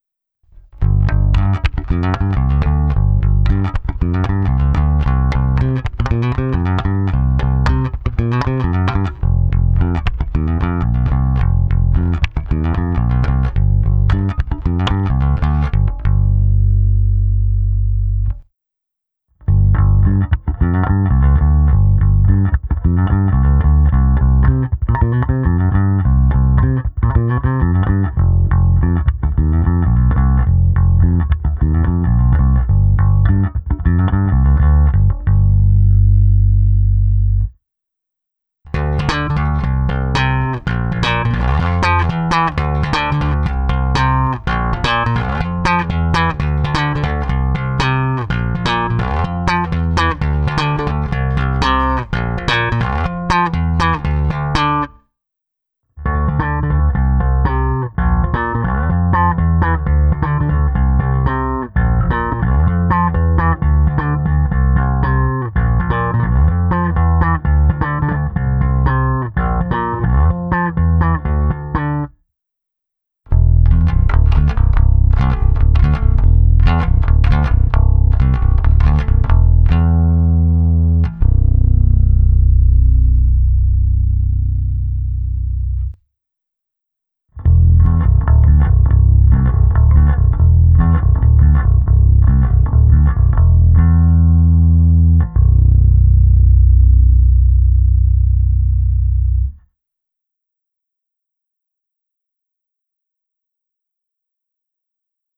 Struny nejsou jako třeba D'Addario Chromes zvonivé až cinkavé, ale mají příjemný měkkčí podání s výraznými středy, s takovými, co v kapele pěkně tmelí zvuk.
Dvojmo, protože nejdřív je vždy část bez a pak se simulací aparátu. První ukázka je klasická prstová technika, pak hra právě slapem a nakonec prstové hraní s využitím struny H. Použitá baskytara je Fender American Professional II Precision Bass V.